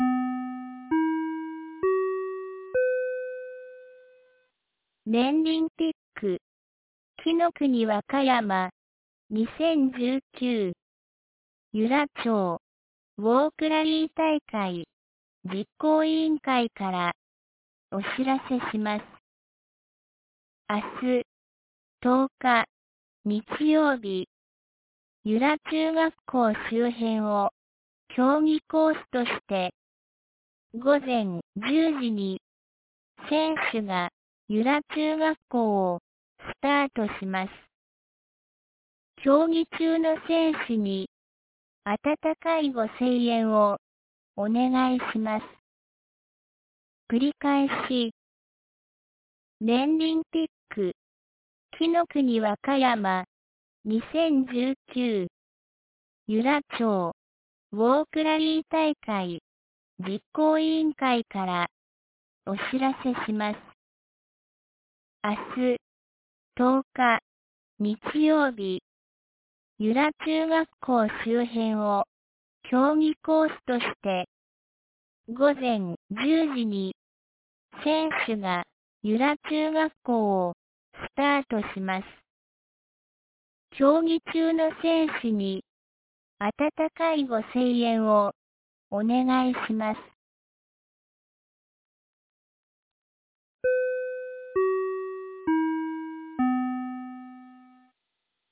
2019年11月09日 12時22分に、由良町から全地区へ放送がありました。